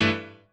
piano10_5.ogg